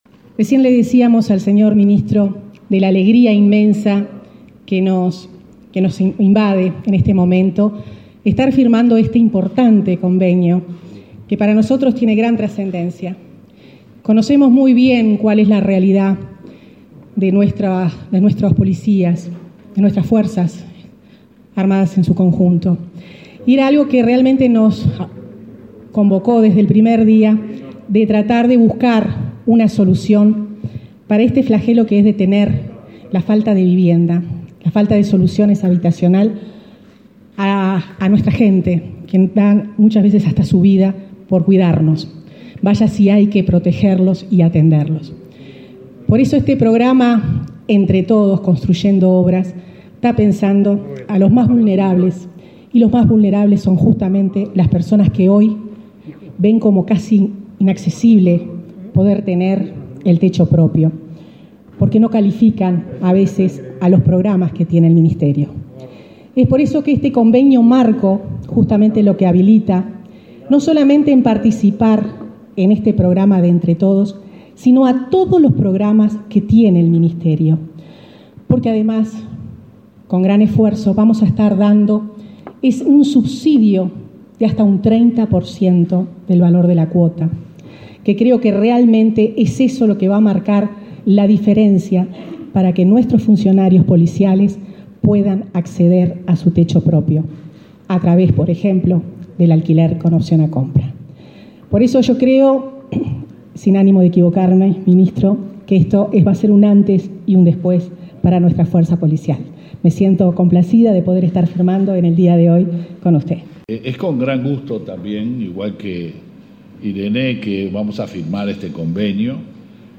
Palabras de los ministros de Vivienda e Interior